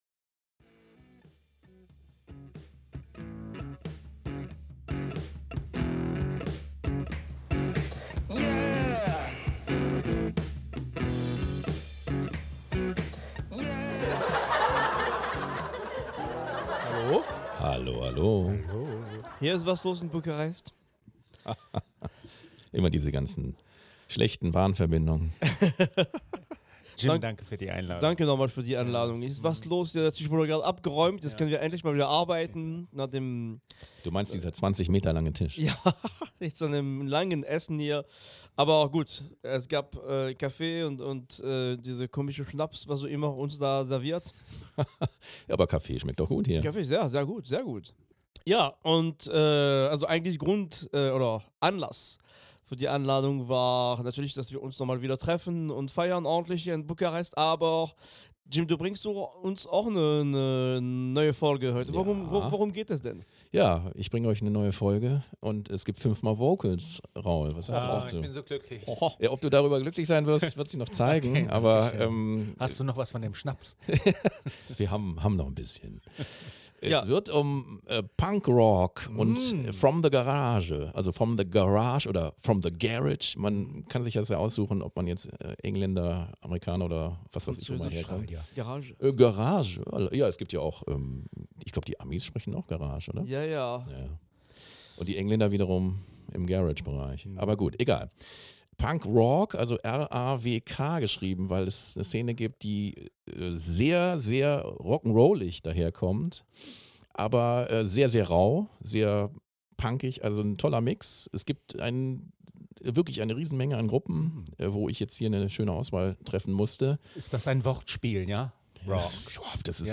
Laut, schnell und dreckig.